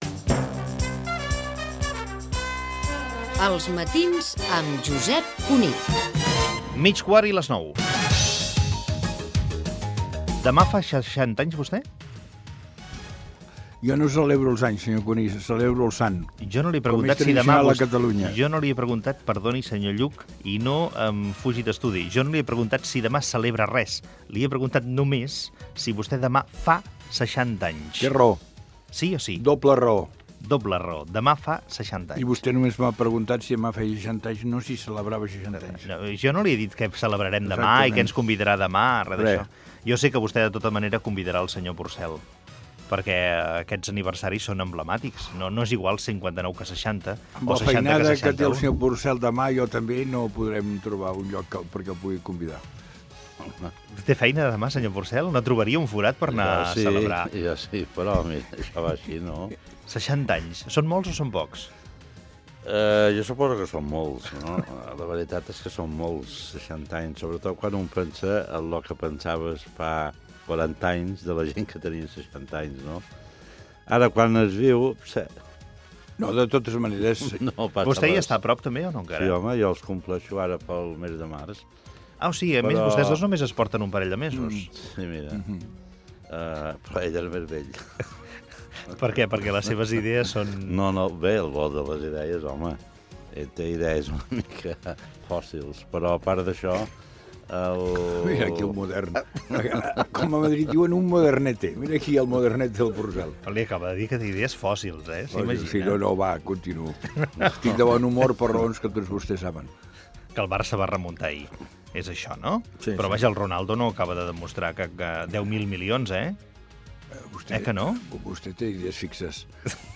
Indicatiu del programa, hora i fragment de la secció "Foc creuat" amb Ernest Lluch i Baltasar Porcel sobre l'edat i el pas del temps.
Info-entreteniment
FM